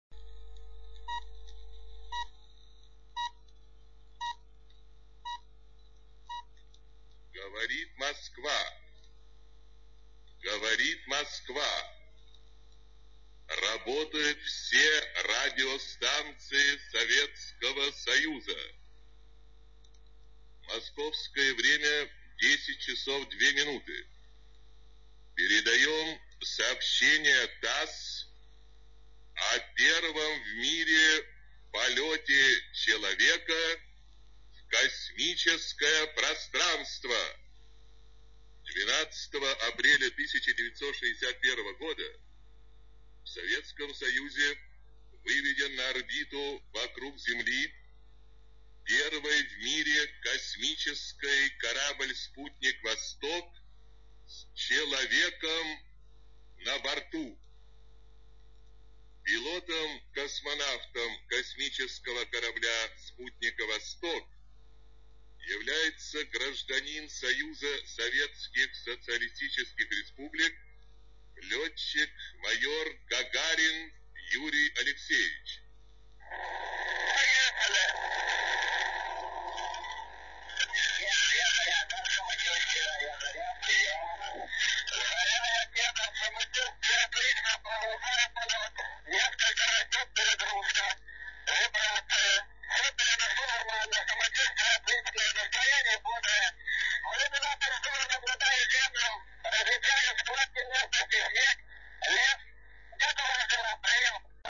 Радиотрансляция 12.04.1961